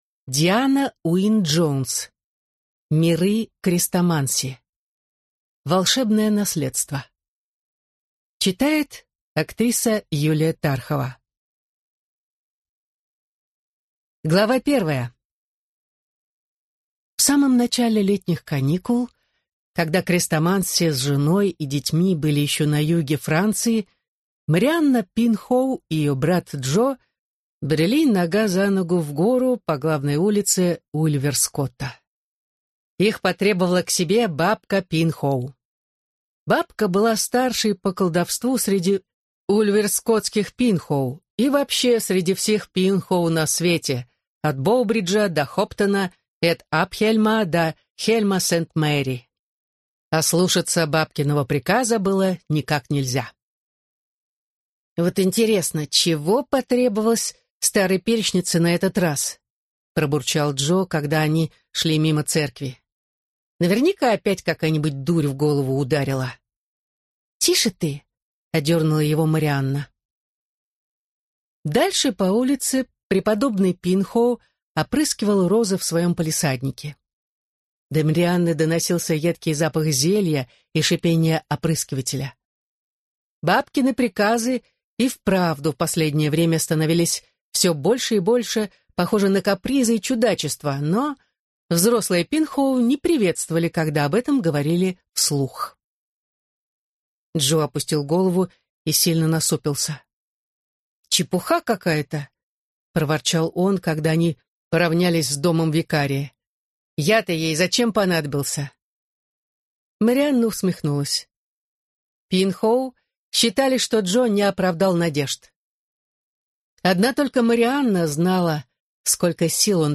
Аудиокнига Волшебное наследство | Библиотека аудиокниг
Прослушать и бесплатно скачать фрагмент аудиокниги